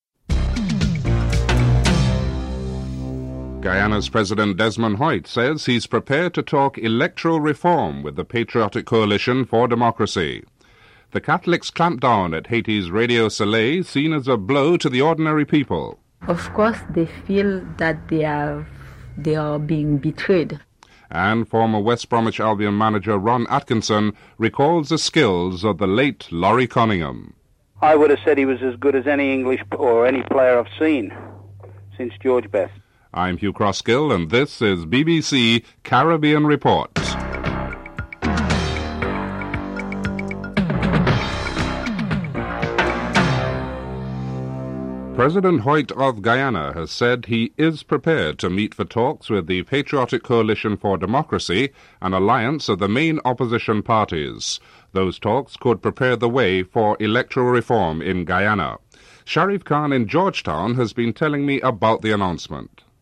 1. Headlines (00:00-00:35)
3. Financial News (04:21-06:04)